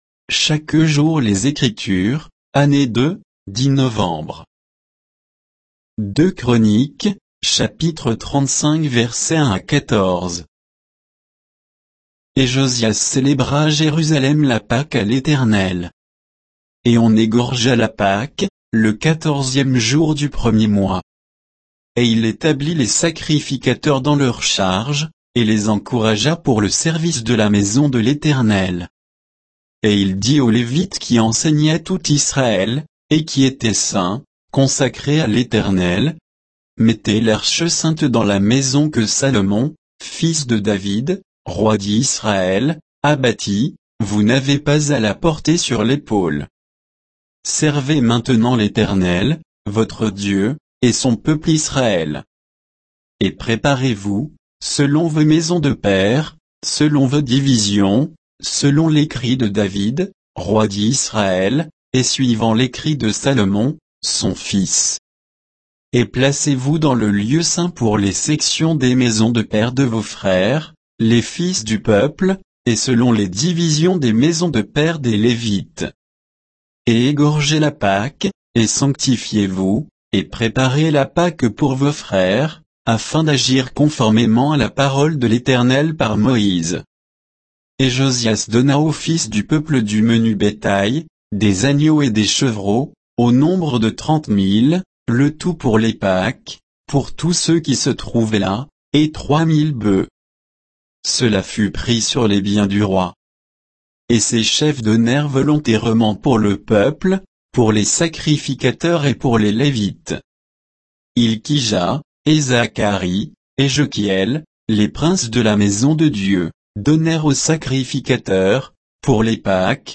Méditation quoditienne de Chaque jour les Écritures sur 2 Chroniques 35, 1 à 14